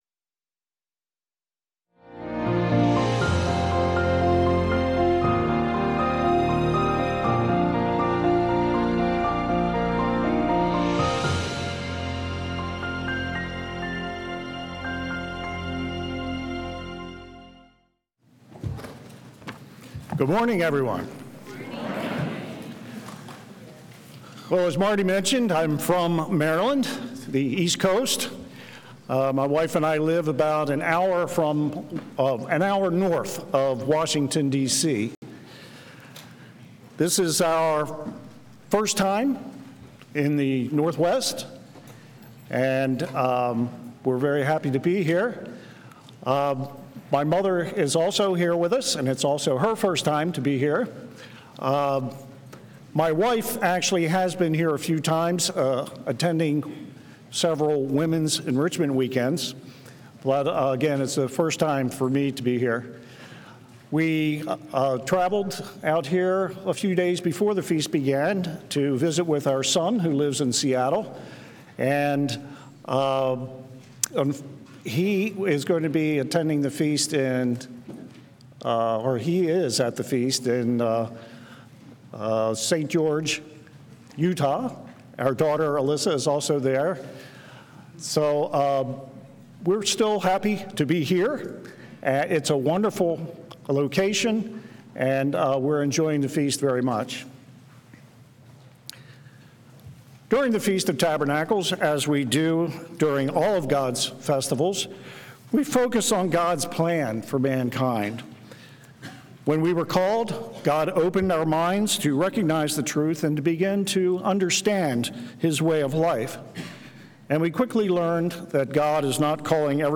This sermon was given at the Spokane Valley, Washington 2023 Feast site.